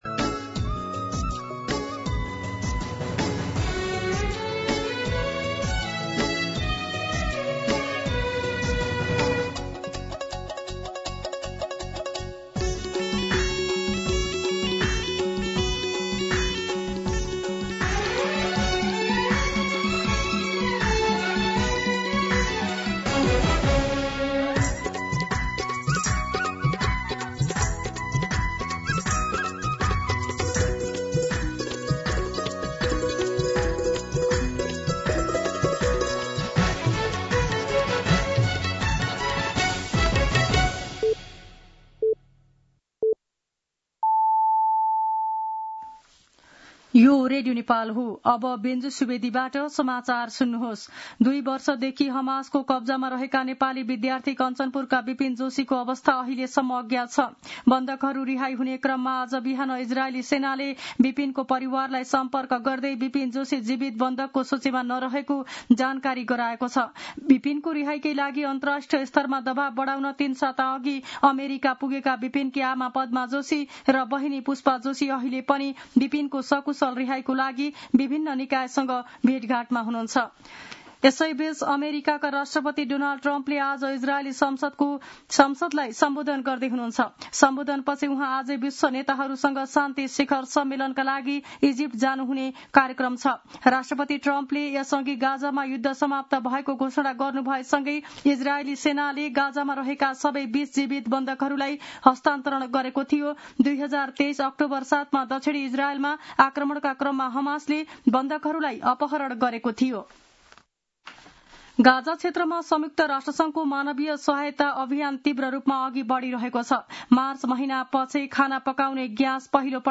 दिउँसो ४ बजेको नेपाली समाचार : २७ असोज , २०८२
4-pm-Nepali-News-5.mp3